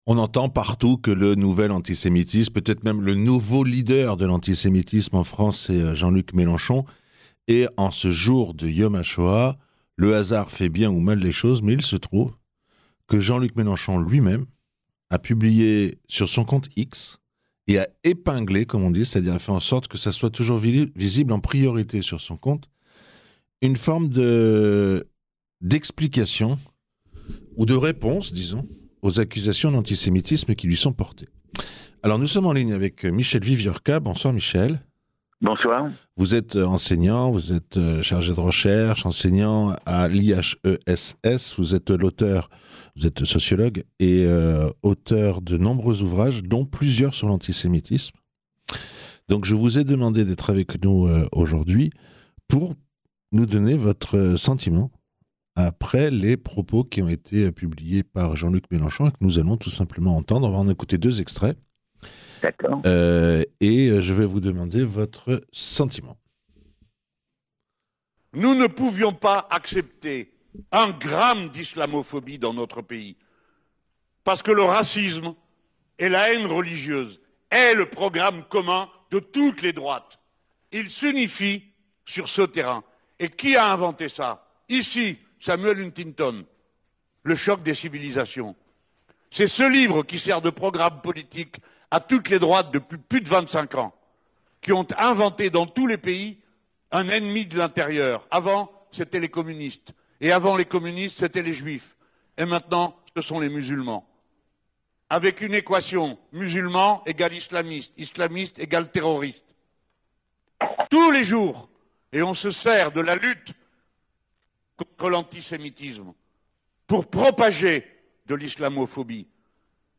24 avril 2025 - 18:48 - 2227 vues Écouter le podcast Télécharger le podcast Directeur d'études à l'EHESS, Michel Wieviorka est l’auteur de nombreux ouvrages dont plusieurs sur l’antisémitisme.